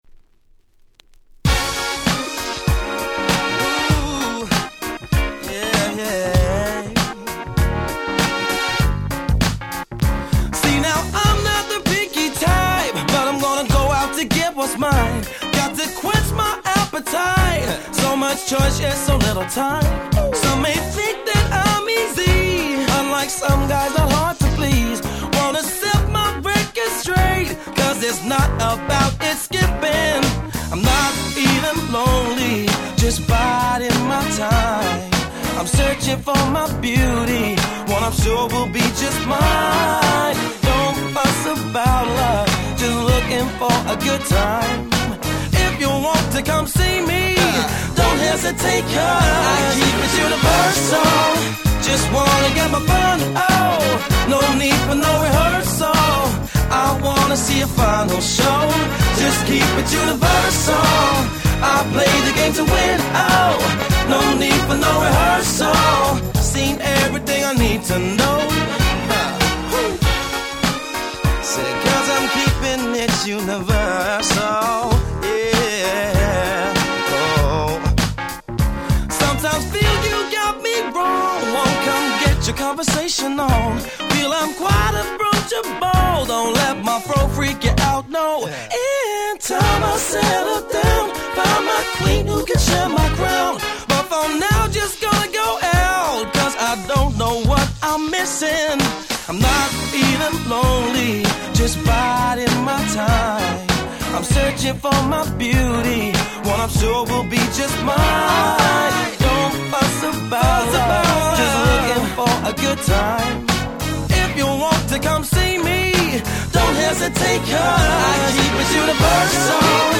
05' Super Nice UK R&B/Neo Soul !!